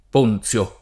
Ponzio [p0nZLo o
p1nZLo] pers. m. stor. e cogn.